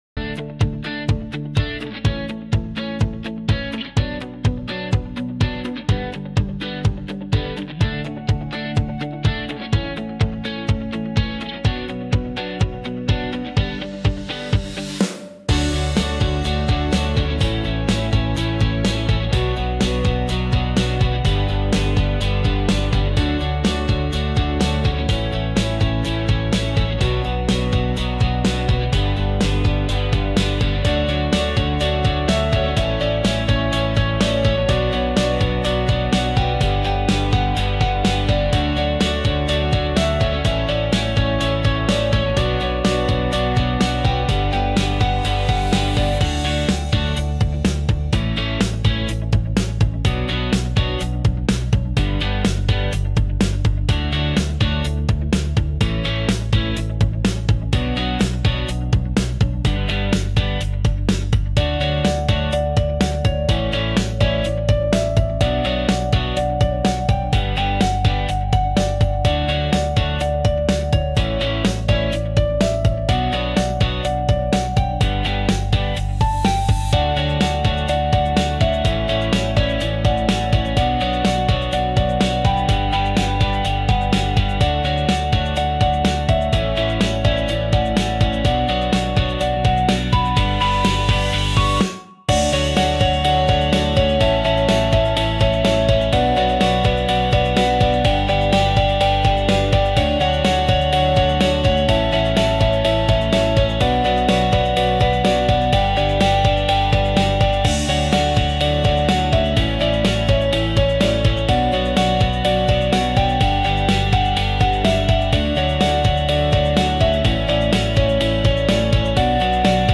スタンダードポップ・インストゥルメンタル・ボーカル無し
明るい